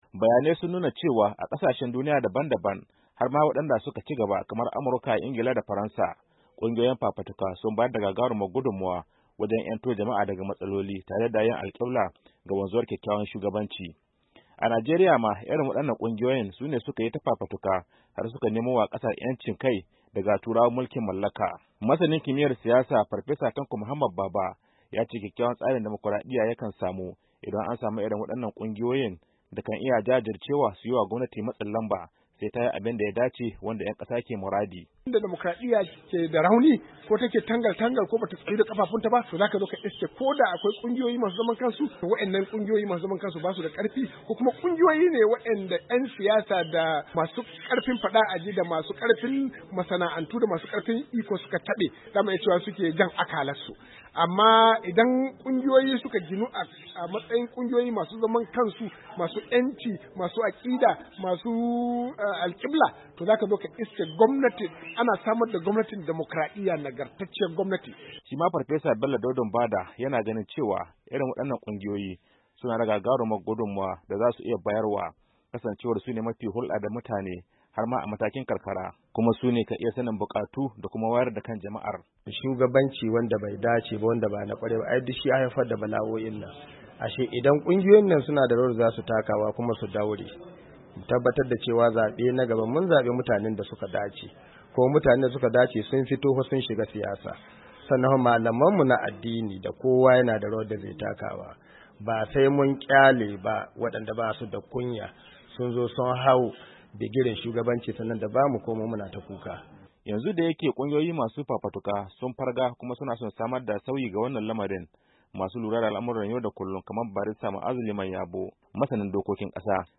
Sokoto, Najeriya —